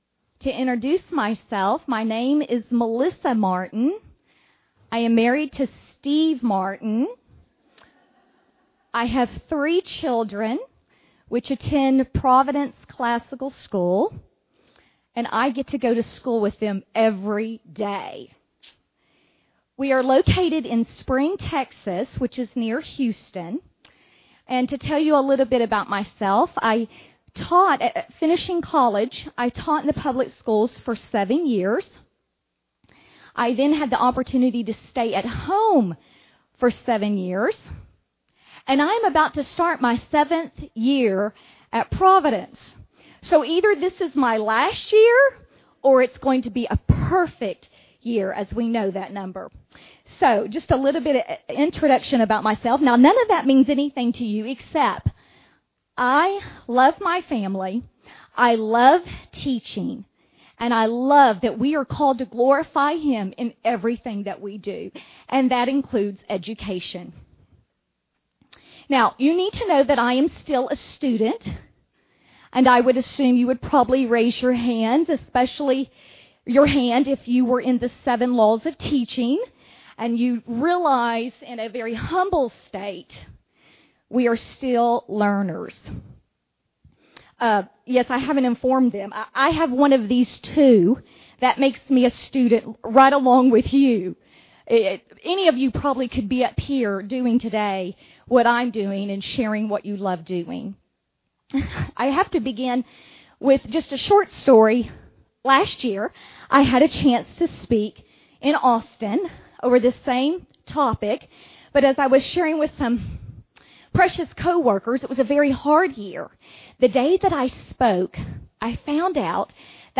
2009 Workshop Talk | 1:08:58 | All Grade Levels, Literature